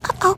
Worms speechbanks
UH-OH.WAV